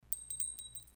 bulletshells04.mp3